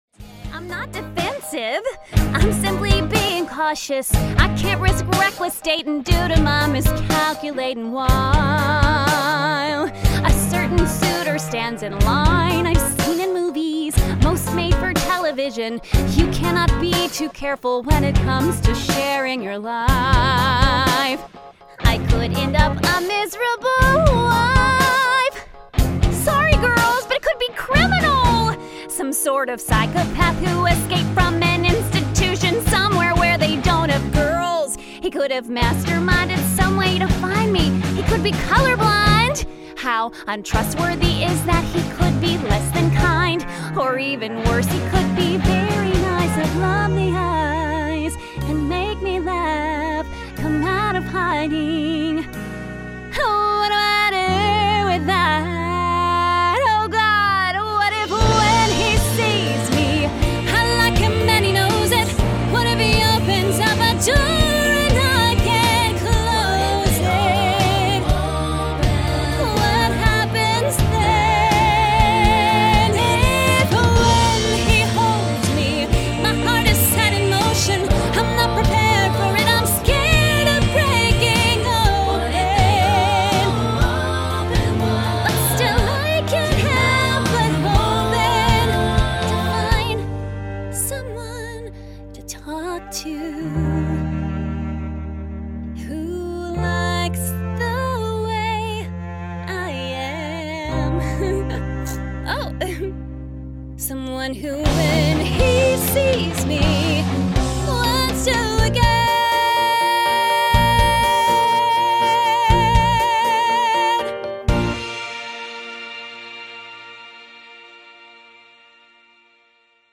Female
Warm and bubbly, your most trustworthy neighbor and bestie!
Singing
Song Demo
Words that describe my voice are Trustworthy, Warm, Friendly.